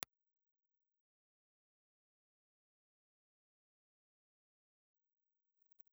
Impulse response file of the BBC-Elettroacustica ribbon microphone.
BBC_Italy_IR.wav